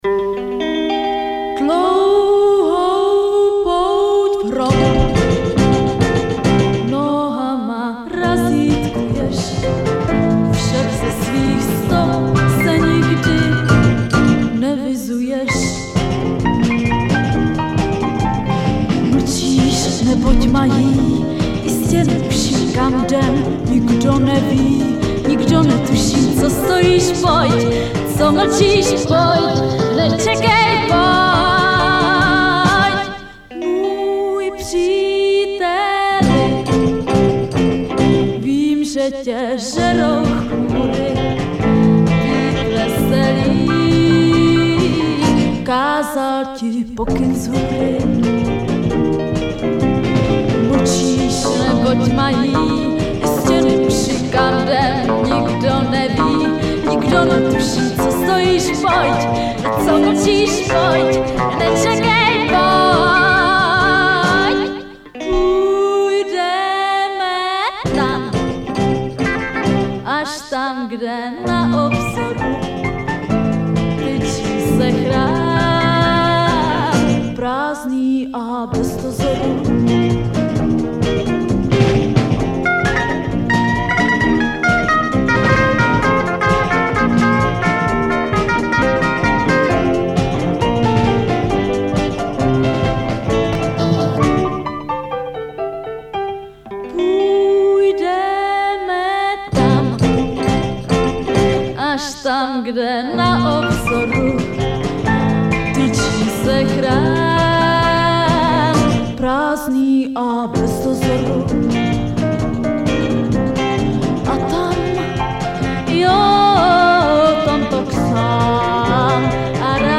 prázdninový pojízdný písničkový kabaret CHRÁM Text
Nahráno na jevišti Divadla Vítězslava Nezvala v Karlových Varech, 1968. zobrazit celý dlouhý text Rok